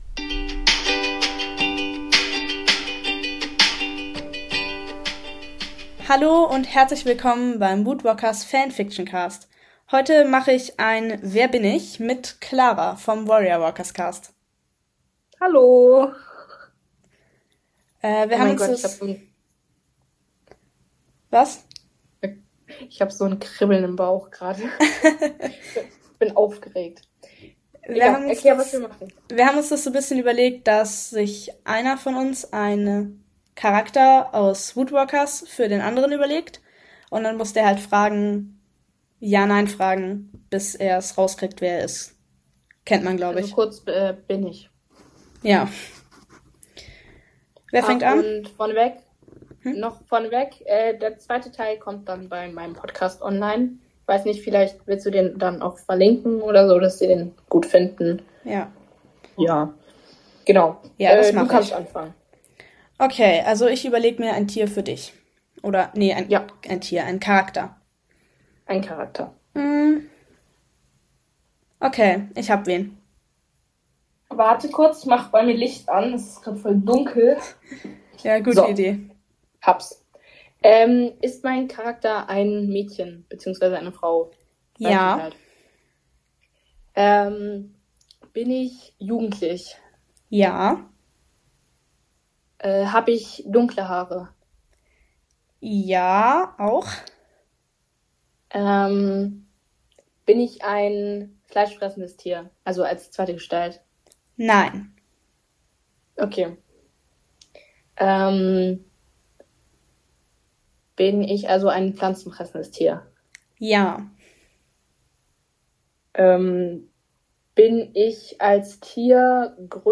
In dieser Folge Sensation Nach über 200 Folgen nehme ich endlich mal mit einer anderen Podcasterin zusammen auf Ich hoffe, man hat uns beide gut gehört usw..